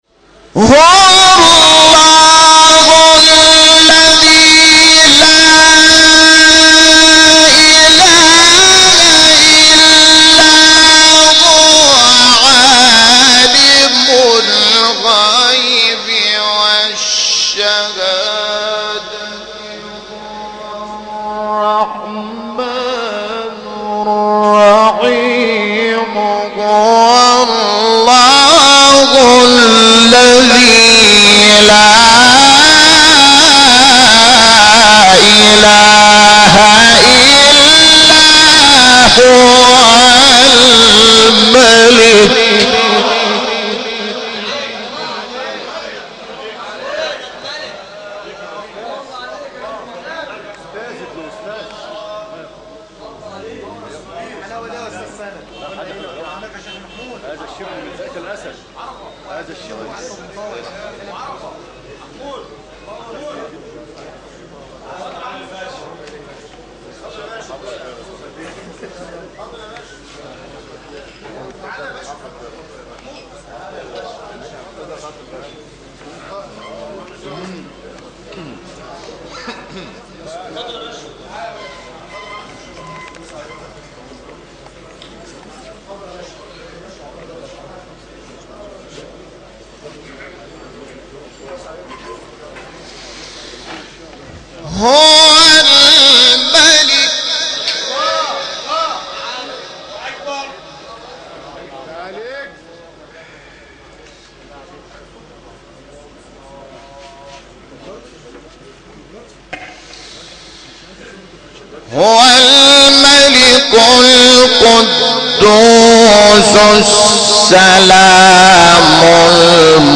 سوره : حشر آیه: 22-23 استاد : محمود شحات مقام : بیات قبلی بعدی